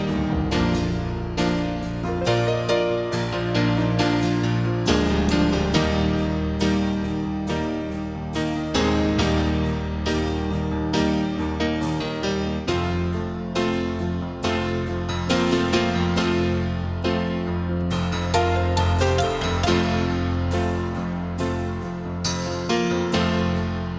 Two Semitones Down Pop (1970s) 5:38 Buy £1.50